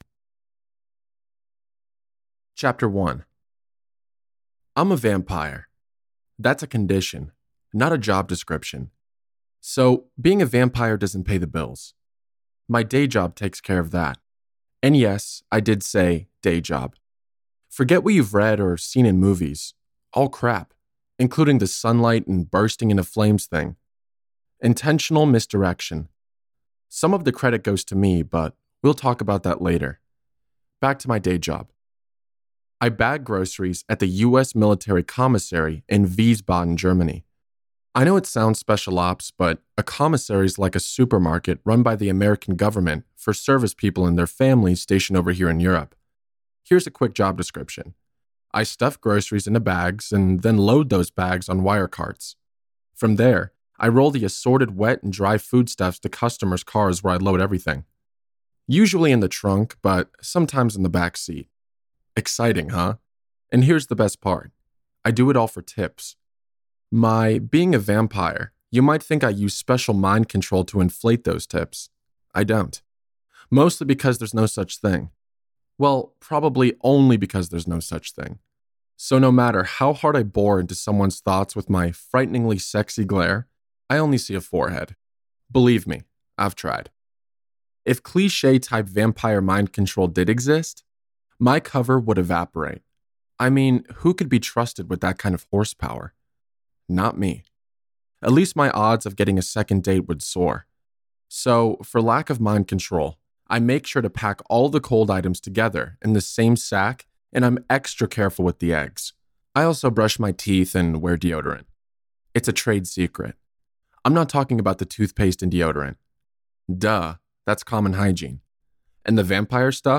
Bones and Bagger Audiobook Sample